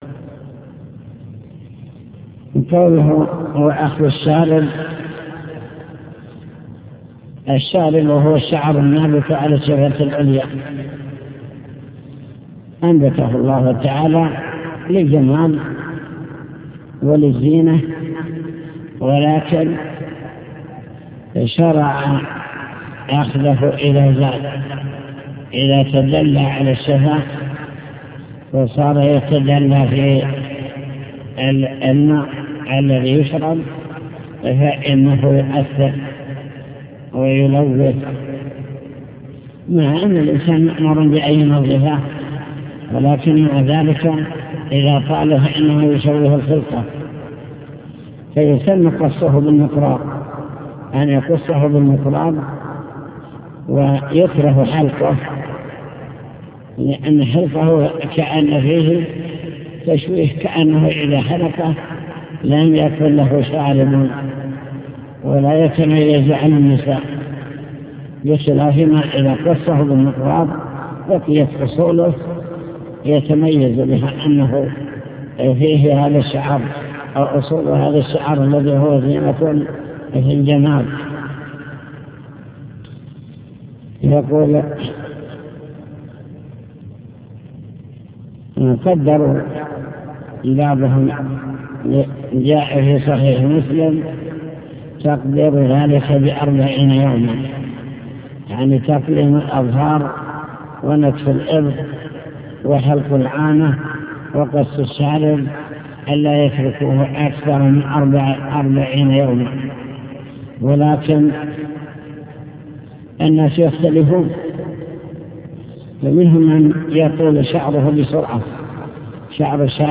المكتبة الصوتية  تسجيلات - كتب  شرح كتاب دليل الطالب لنيل المطالب كتاب الطهارة سنن الفطرة